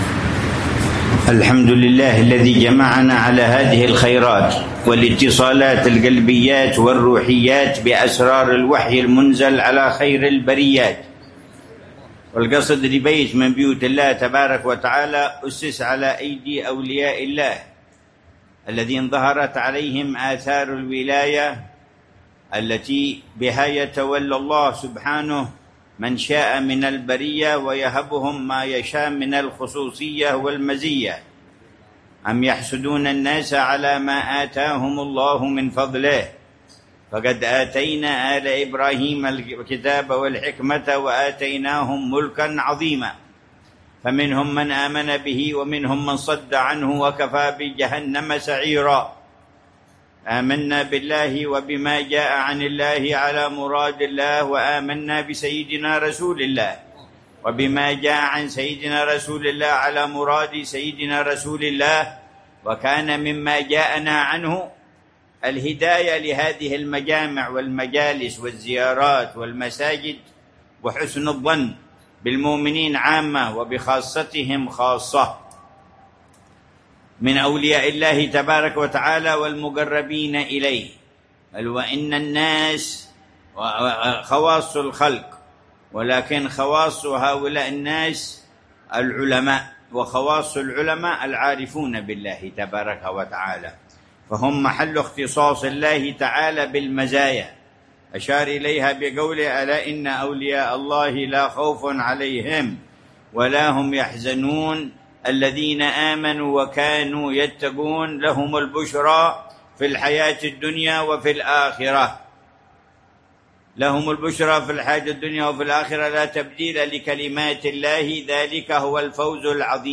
مذاكرة العلامة الحبيب عمر بن محمد بن حفيظ في مسجد الشيخ أحمد باعيسى، بقرية اللسك، شرقي مدينة تريم، ليلة السبت 5 ربيع الثاني 1447هـ بعنوان: